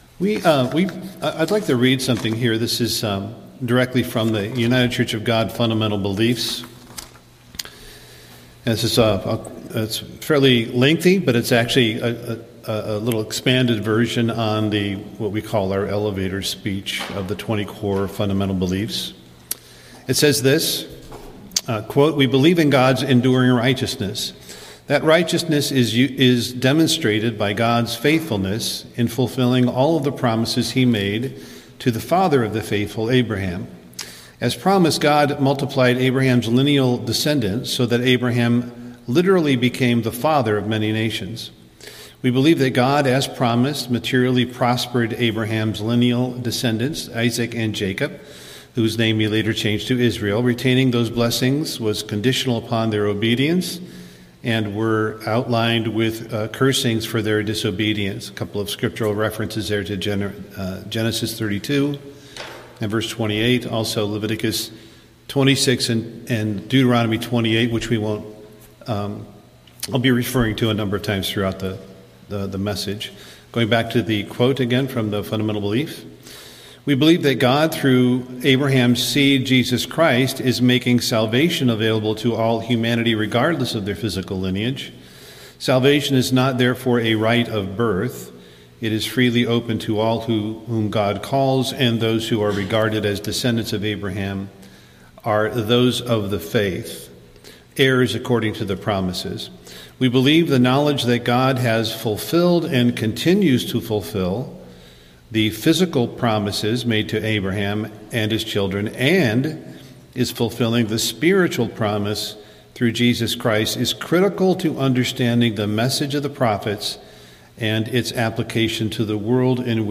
Sermons
Given in Twin Cities, MN